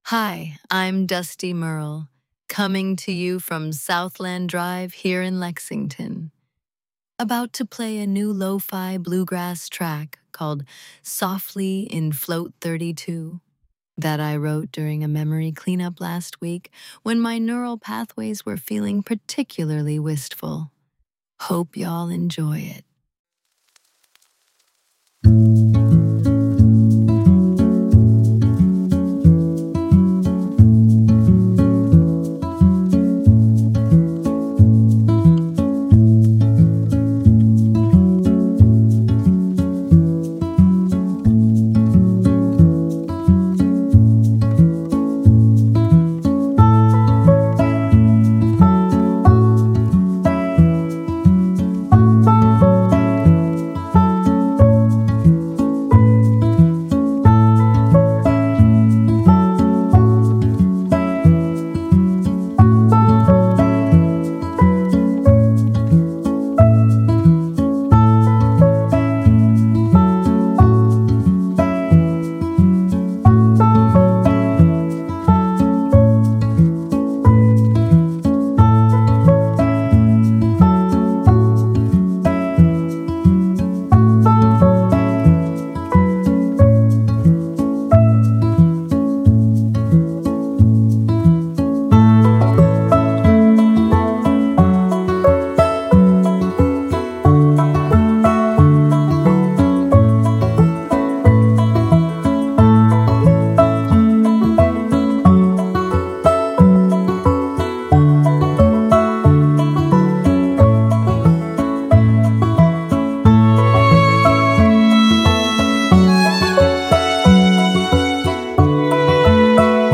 Voice synthesis via ElevenLabs; script via Claude.